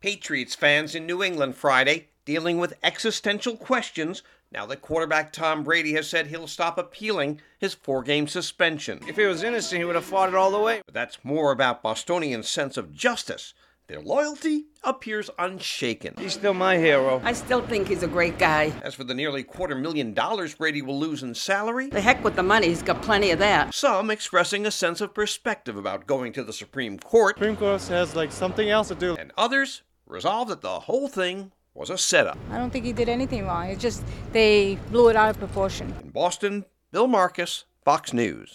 OTHER VOICES FROM THE STREETS OF NEW ENGLAND FRIDAY: